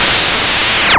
se_lazer00.wav